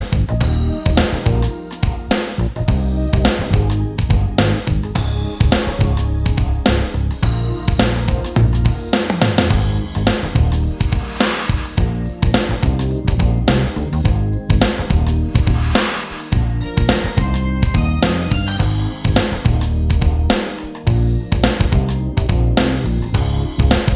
Minus Brass And All Guitars R'n'B / Hip Hop 4:27 Buy £1.50